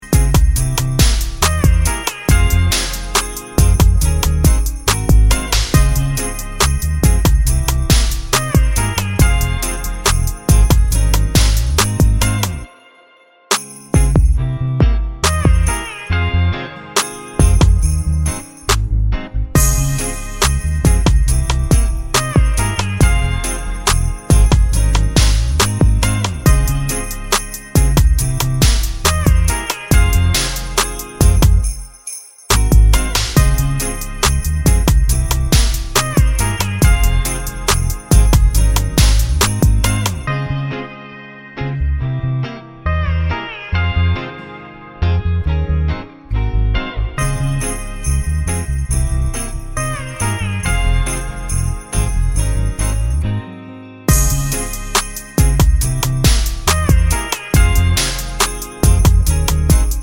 no Backing Vocals Christmas 2:34 Buy £1.50